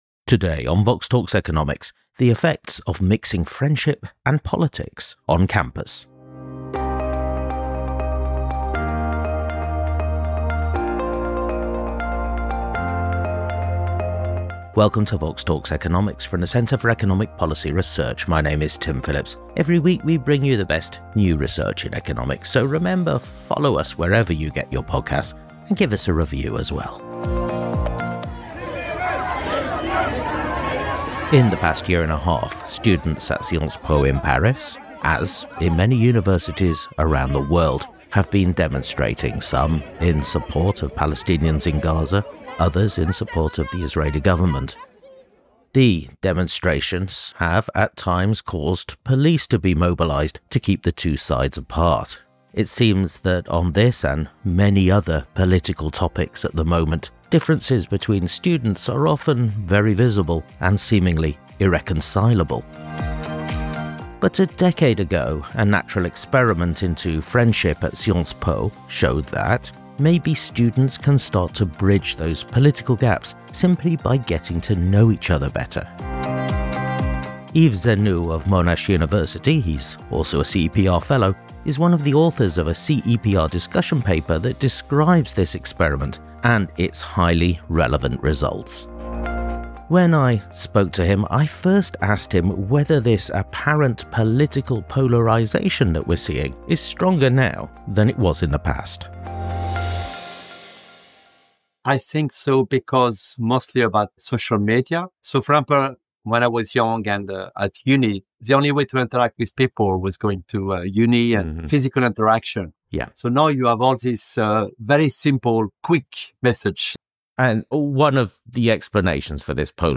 We spoke to him about how friendship can close political gaps, and how to create dialogue on campus and in society.